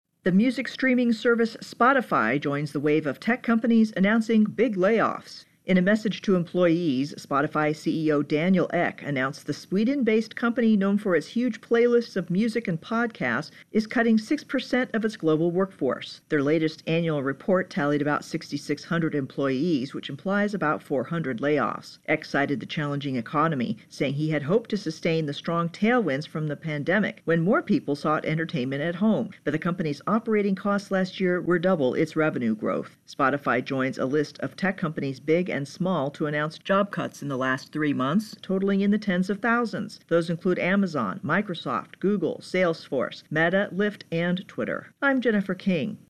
reports on Spotify Layoffs.